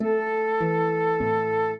flute-harp
minuet7-2.wav